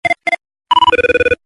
座机铃声一（内线打来）